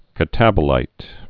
(kə-tăbə-līt)